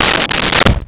Dresser Drawer Open 04
Dresser Drawer Open 04.wav